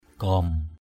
/ɡ͡ɣɔ:m/ 1.